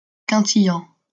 Quintillan (French pronunciation: [kɛ̃tijɑ̃]